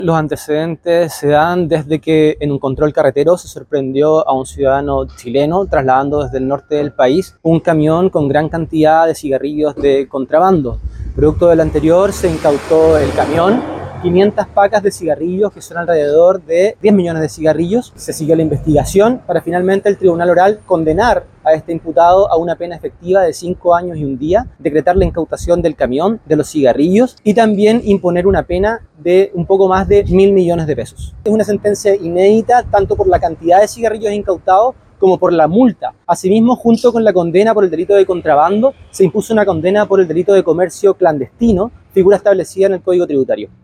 AUDIO-FISCAL-PEDRO-PABLO-ORELLANA.mp3